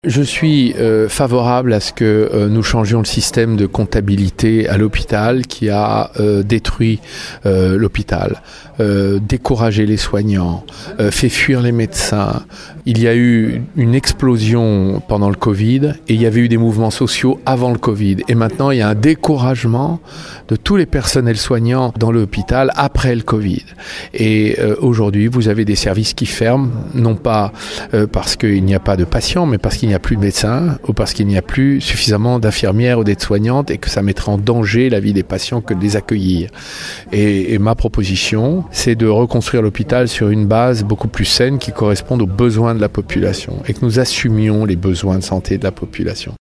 Le député de Saône-et-Loire a insisté sur les problèmes de désertification médicale et d’inégalité d’accès aux soins.